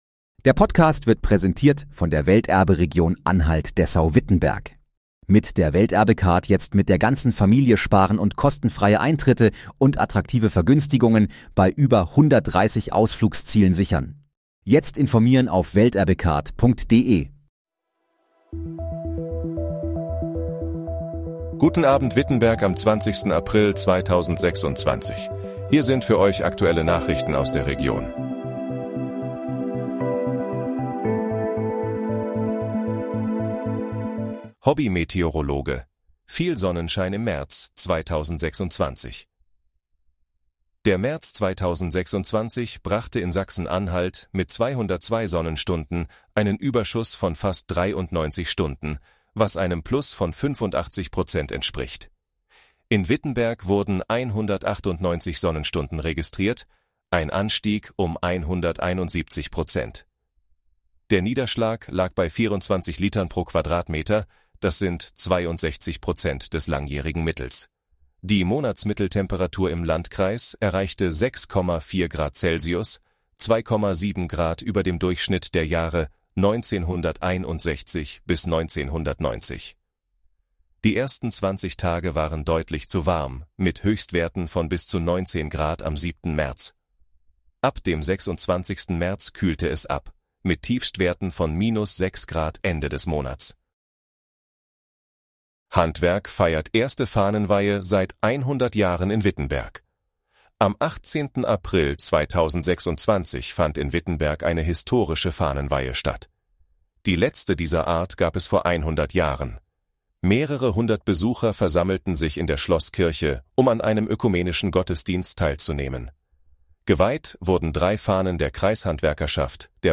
Guten Abend, Wittenberg: Aktuelle Nachrichten vom 20.04.2026, erstellt mit KI-Unterstützung